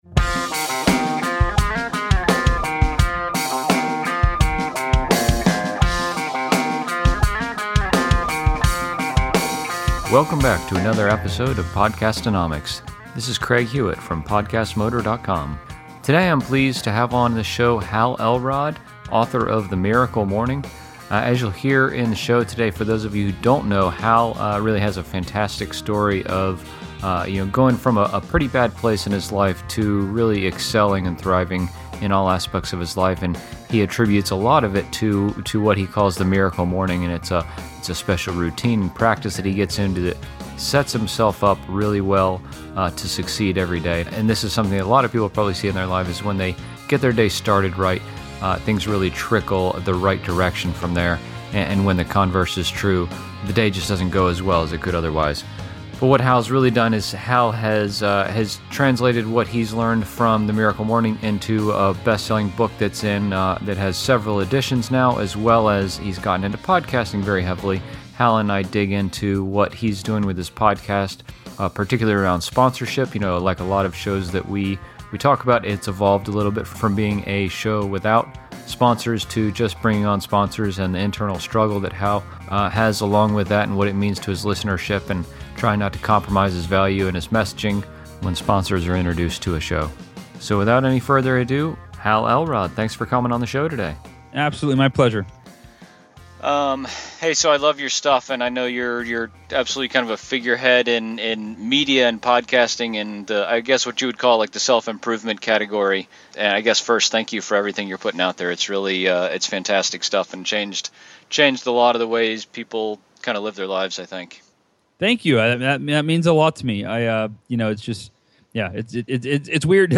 Today I'm excited to welcome Hal Elrod, author of The Miracle Morning and host of the Achieve Your Goals podcast, to the show.
In this episode Hal and I talk about how he got started in podcasting, what he's learned since Achieve Your Goals started a year and a half ago, and some of his tips for all podcasters out there. From a business sense Hal has seen his podcast be a great source of audience building, growing his community of followers of The Miracle Morning practice.